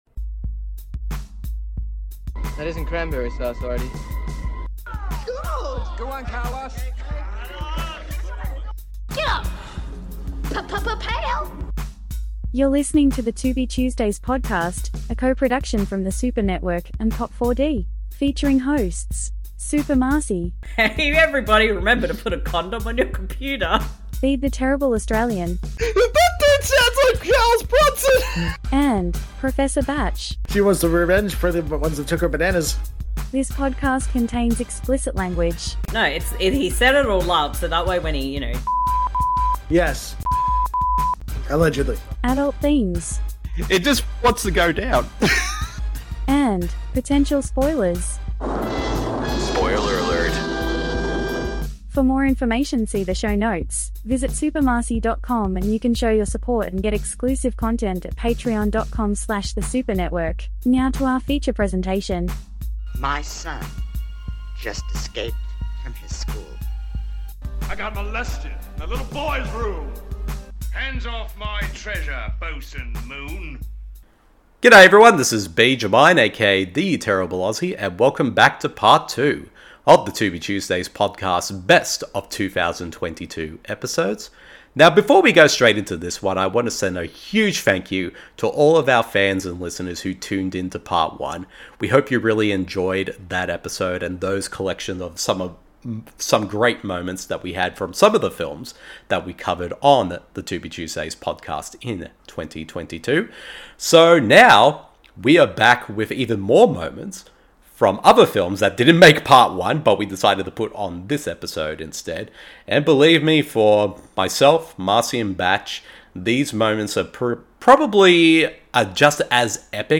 Welcome to Part 2 of our 2 part special with a best of 2022 compilation of funny and memorable moments for The Tubi Tuesdays Podcast for 2022!